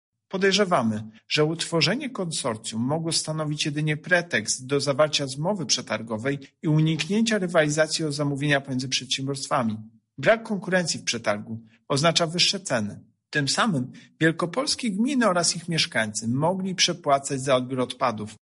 Konsorcjum, które wygrało większość konkursów tworzyły przede wszystkim duże spółki mogące startować w przetargu samodzielnie lub z mniejszą liczbą podmiotów – mówi prezes UOKiK Tomasz Chróstny: